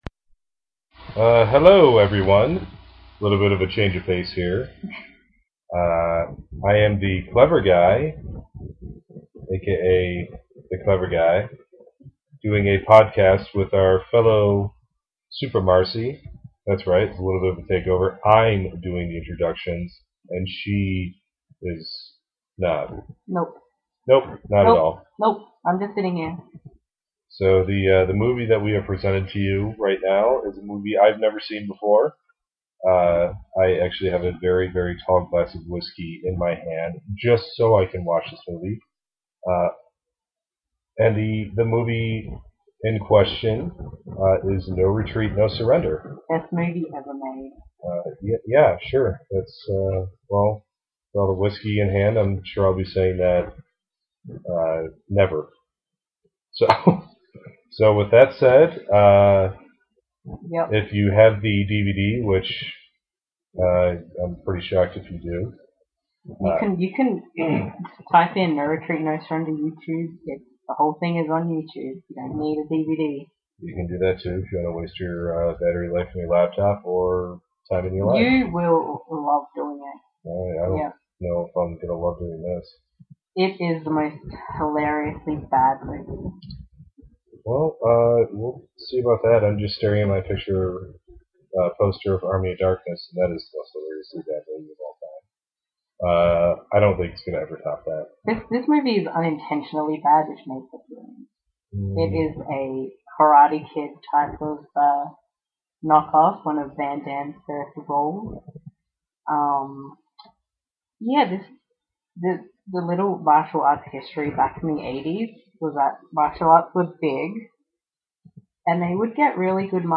We came armed with lots of alcohol and you can hear the words of a man who has never seen this film and all of his reactions to the amazing scenes, whilst the film professional can’t stop laughing, this is a must listen to commentary!